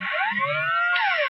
SERVO SE07.wav